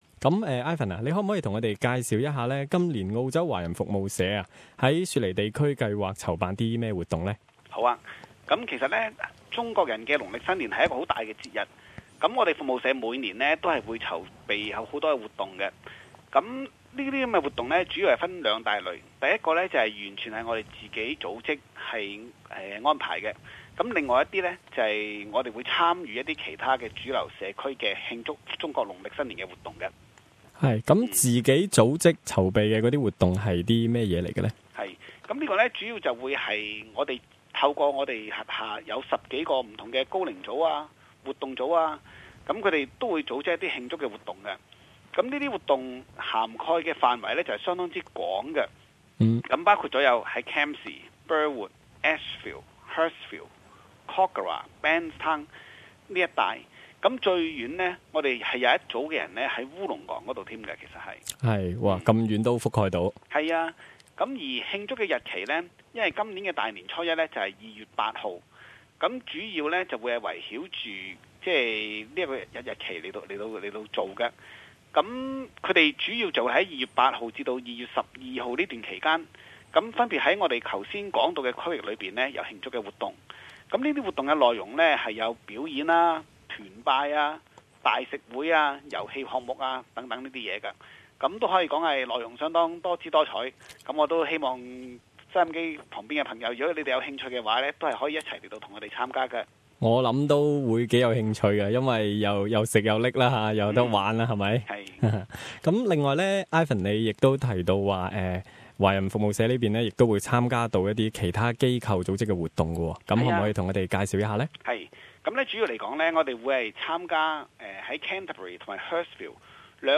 [社区访谈]